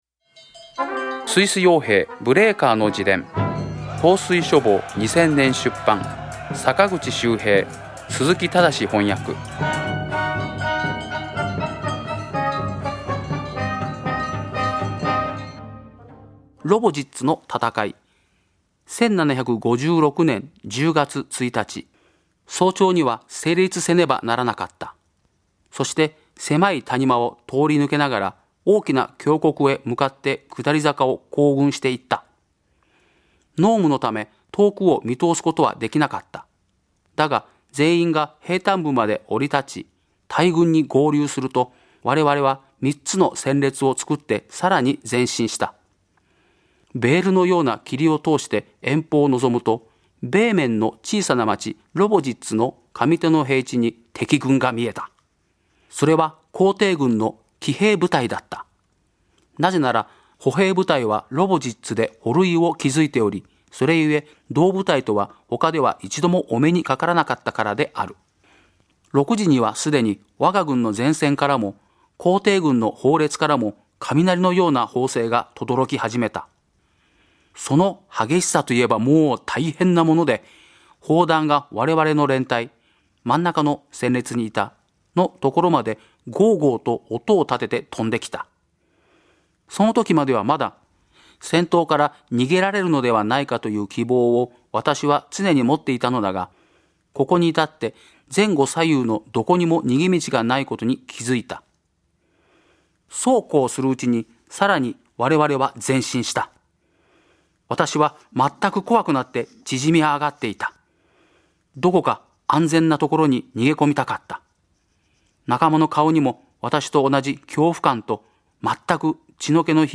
朗読『スイス傭兵ブレーカーの自伝』第59回